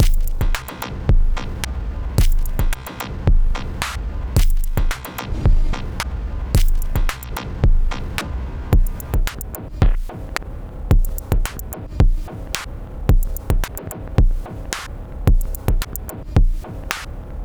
If you’ve never messed with stereo files in the Rytm… it sounds very good! here’s a quick demo of mangling a stereo loop for your enjoyment:
used STROM to tweak both mono tracks at the same time… helpful :grin:
it’s a single pattern, no FX, just looping this file, which was also sampled from Rytm: